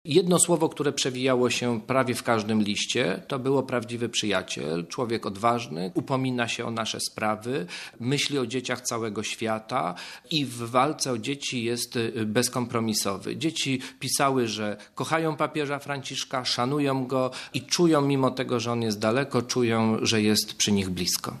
– Razem z orderem papież dostał dziesiątki listów od polskich dzieci – powiedział Marek Michalak, Rzecznik Praw Dziecka i Kanclerz Międzynarodowej Kapituły Orderu Uśmiechu.